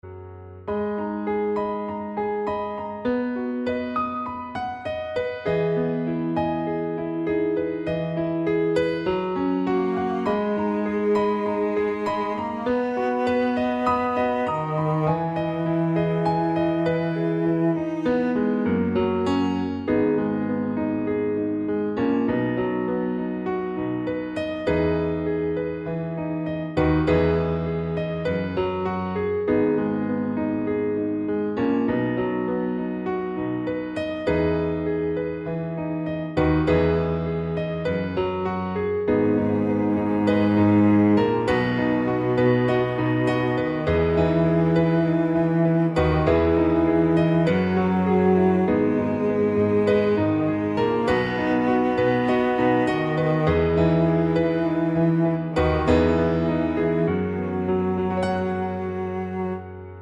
Piano Only Up 3 Semitones